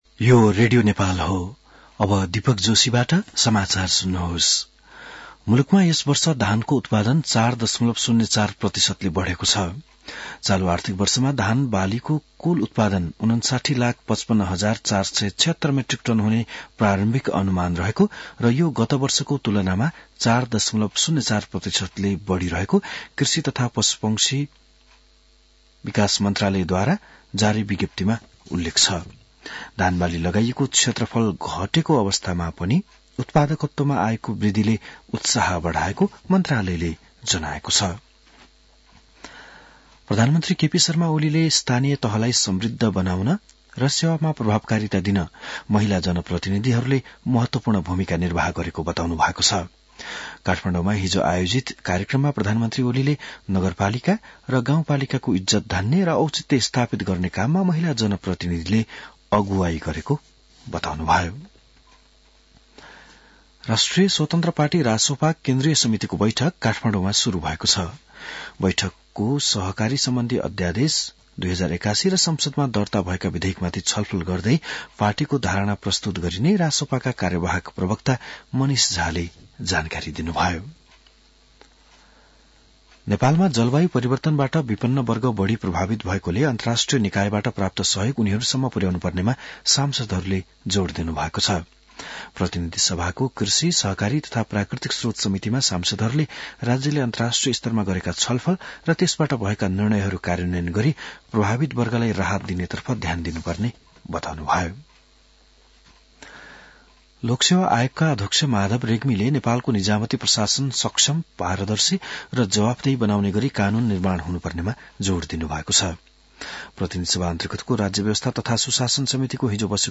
An online outlet of Nepal's national radio broadcaster
बिहान १० बजेको नेपाली समाचार : २१ पुष , २०८१